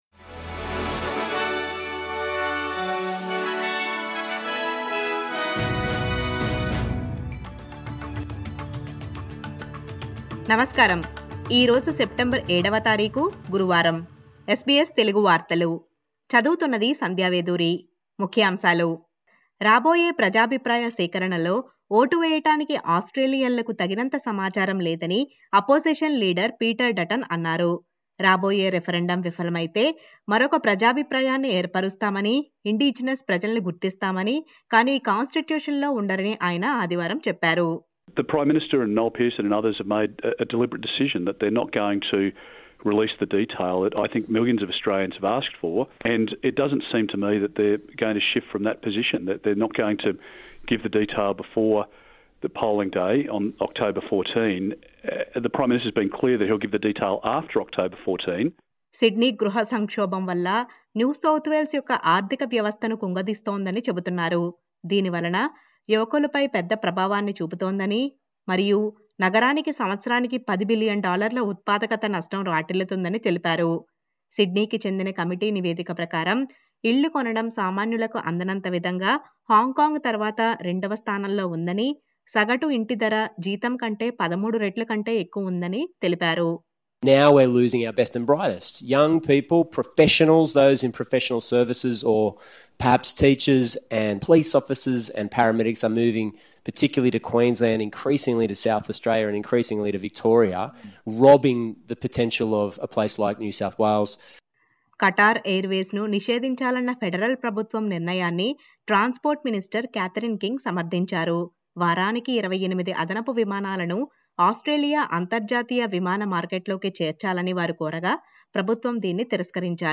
SBS Telugu వార్తలు